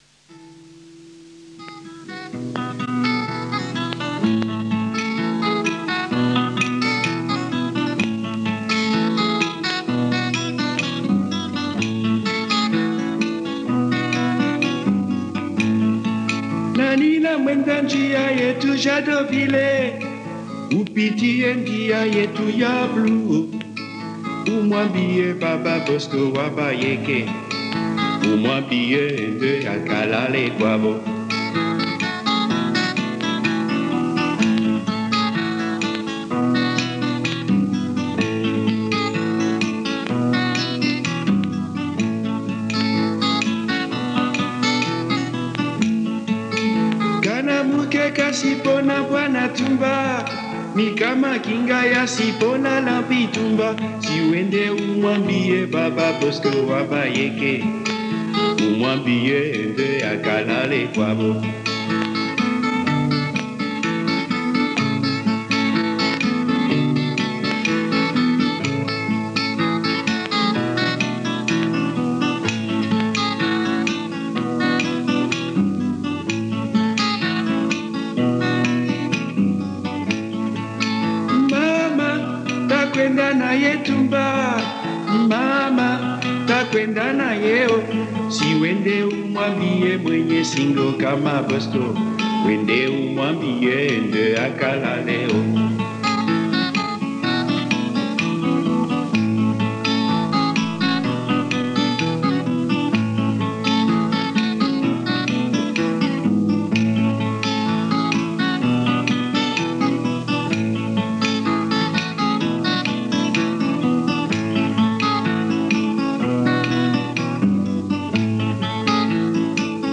Versión instrumental